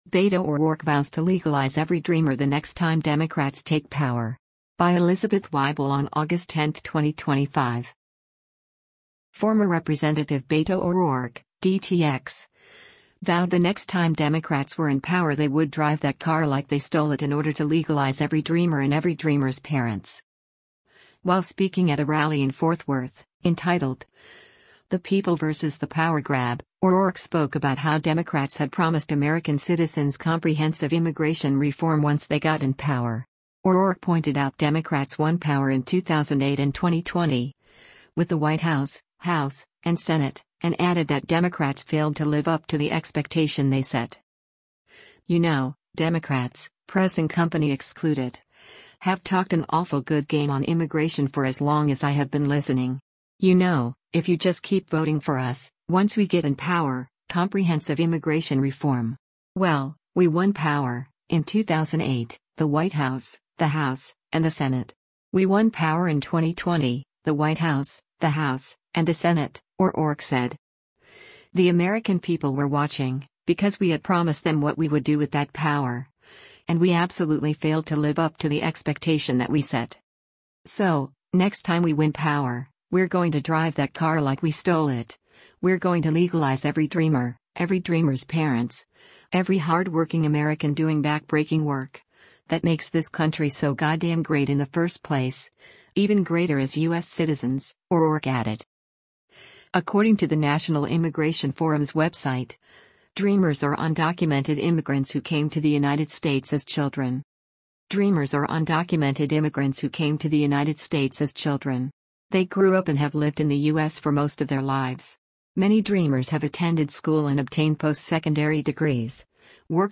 While speaking at a rally in Forth Worth, entitled, “The People vs. The Power Grab,” O’Rourke spoke about how Democrats had promised American citizens comprehensive immigration reform once they got “in power.”